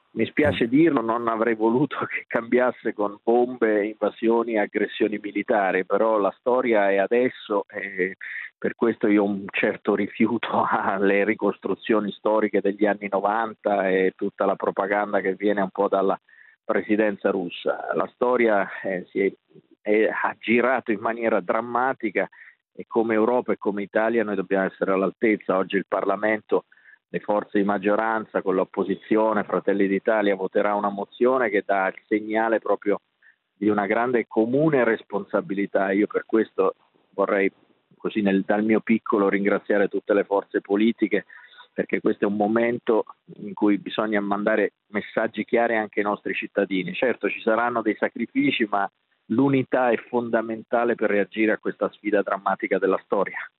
Guerra Ucraina – Amendola, sottosegretario agli Affari Europei, a 24 Mattino su Radio 24: Invio armi a Ucraina segna grande passo in avanti per difesa comune Ue